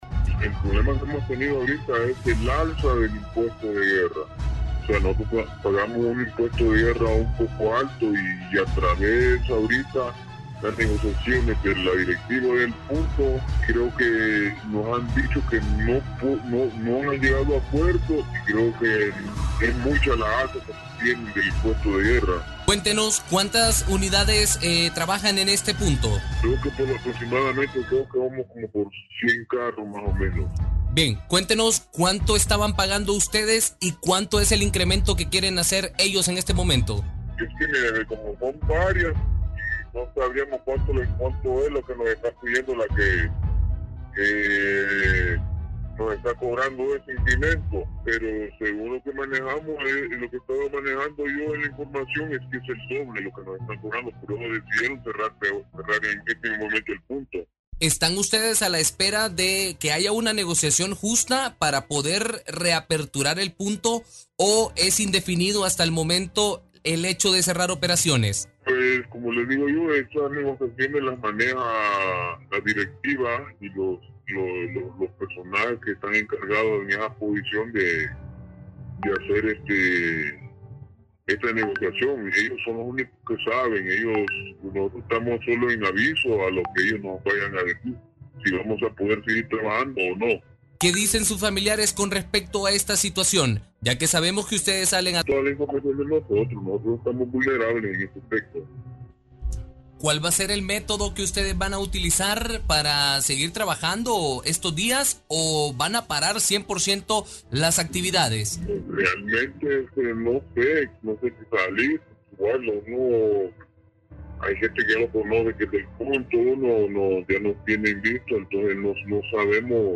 En entrevista con HRN, uno de los taxistas de este punto expuso la situación crítica que se vive entre sus compañeros y el problema que han tenido ante el alza del "impuesto de guerra", debido que han surgido nuevas bandas delincuenciales.